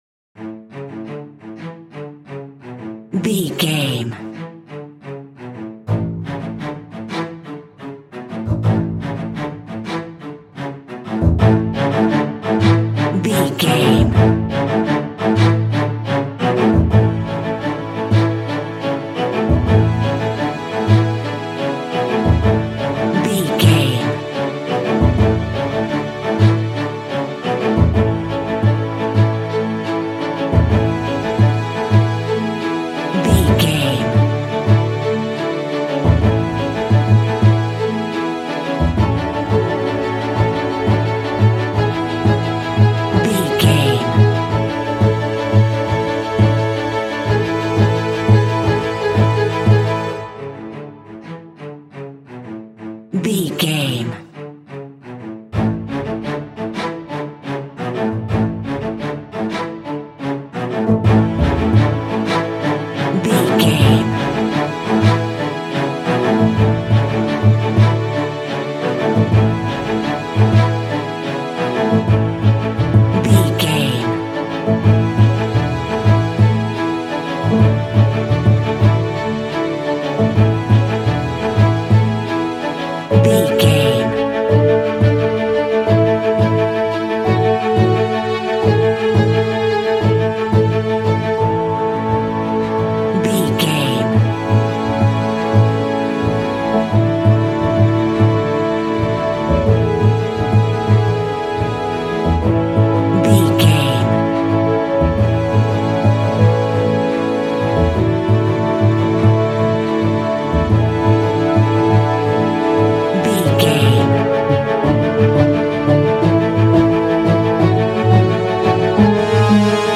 Uplifting
Ionian/Major
soothing
cinematic
contemporary underscore